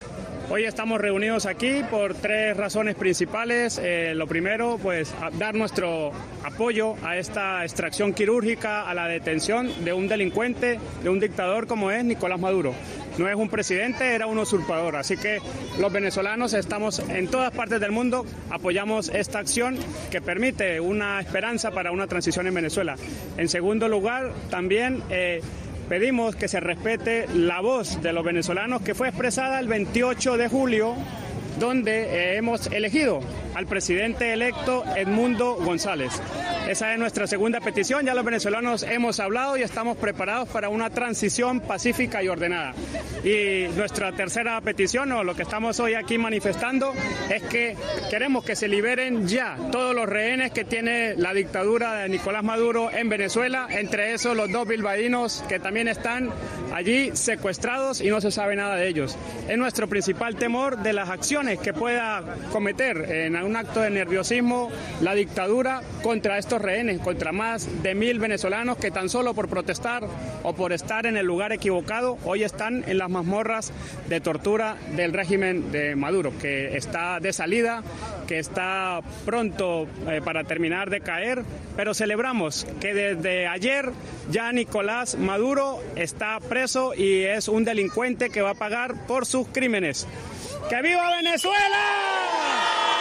Un momento de la concentración frente al Ayuntamiento de Bilbao / RADIO POPULAR/HERRI IRRATIA